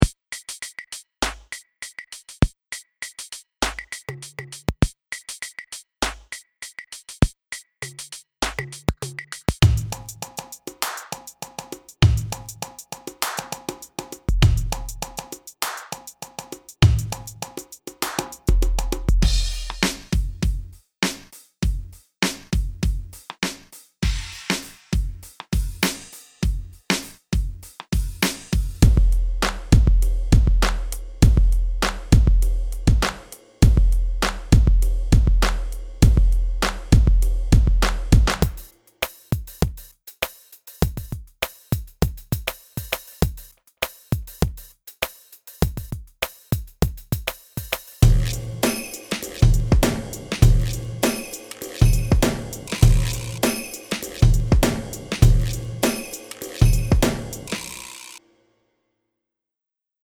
Calliope_Urban_Drum_Demo.mp3